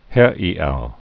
(hĕēou)